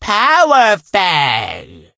fang_ulti_vo_04.ogg